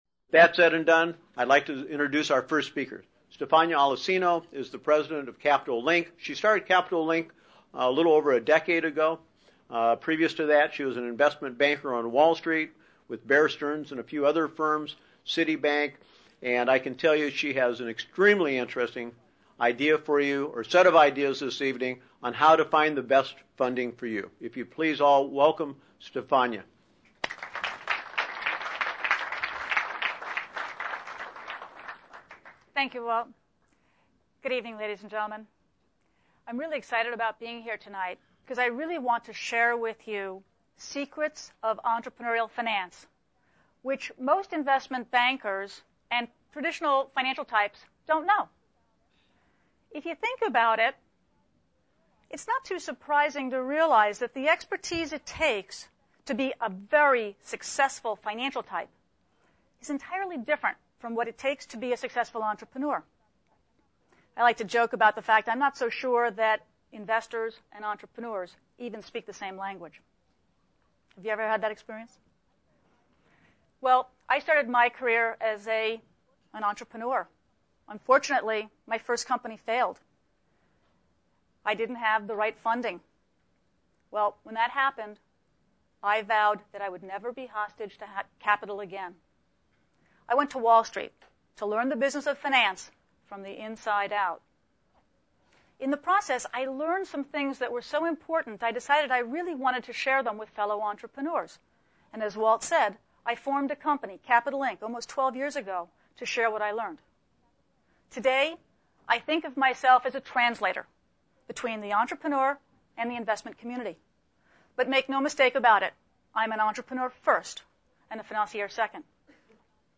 Secrets of Entrepreneurial Finance: How to Select the Best Investor - Presented at Business Sense Seminar Securing Funding: Angle’s, VC or IPO Listen now (streaming mp3) PDF version - workbook Download the free RealPlayer if you have problems with the streaming audio